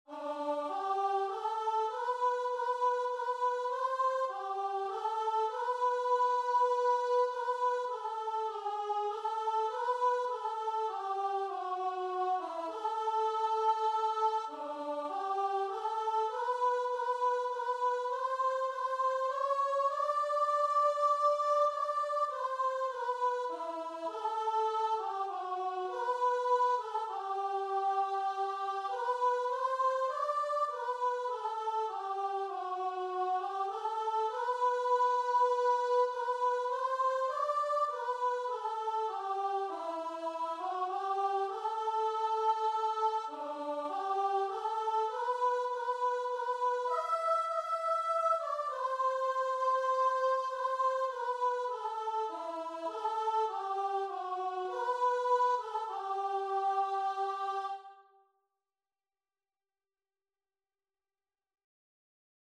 Christian Christian Guitar and Vocal Sheet Music Moment by Moment (Whittle)
Free Sheet music for Guitar and Vocal
Traditional Music of unknown author.
G major (Sounding Pitch) (View more G major Music for Guitar and Vocal )
3/4 (View more 3/4 Music)
Guitar and Vocal  (View more Intermediate Guitar and Vocal Music)
Classical (View more Classical Guitar and Vocal Music)